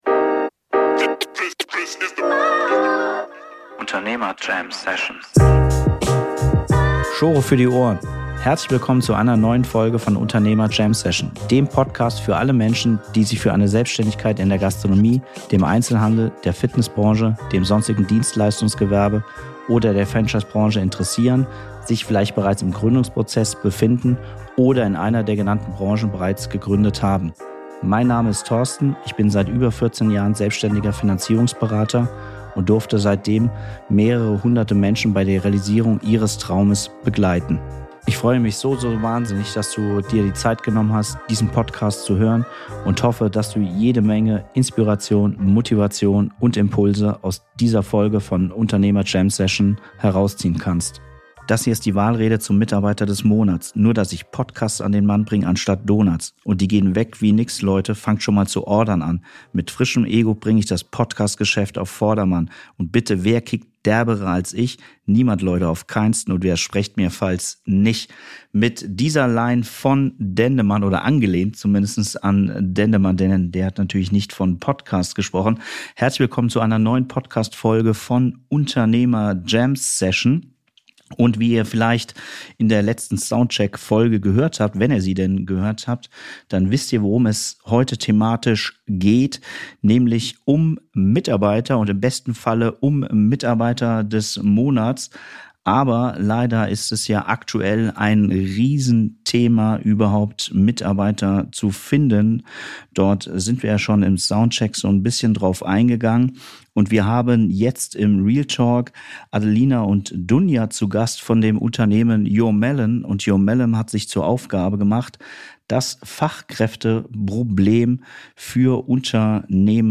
Fachkräfte finden! Wie geht das? Ein Gespräch